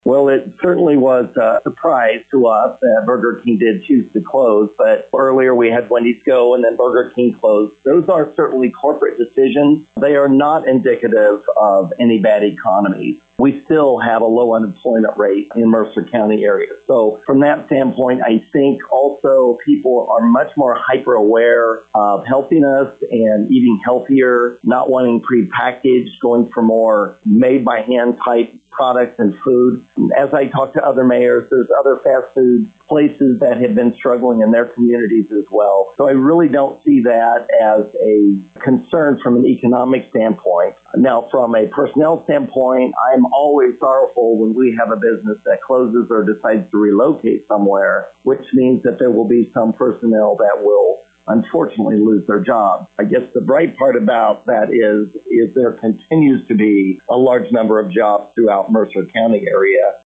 After decades in business, Celina's Burger King has permanently closed. Celina Mayor Jeff Hazel comments: